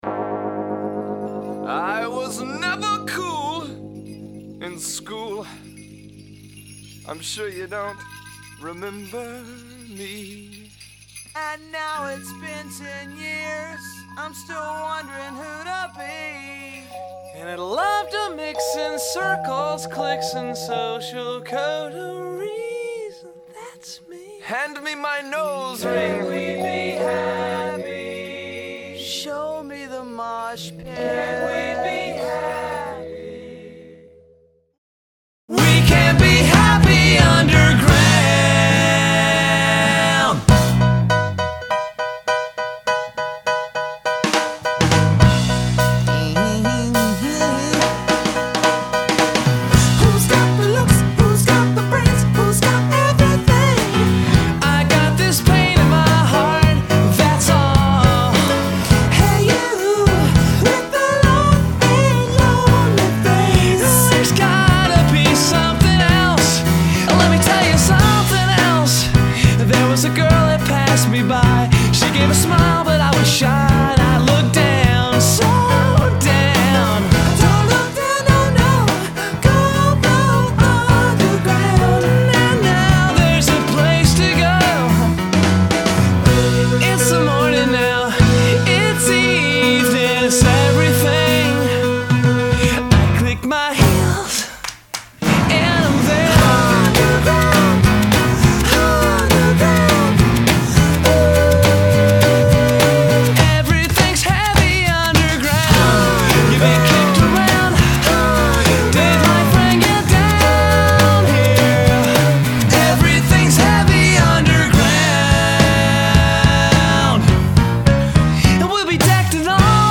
The joyous music has me leaning toward the former.